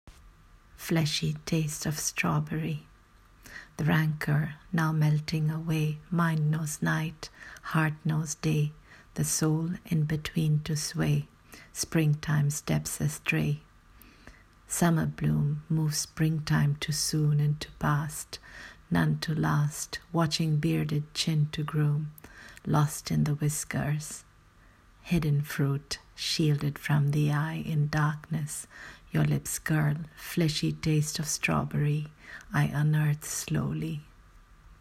Readng of the poem: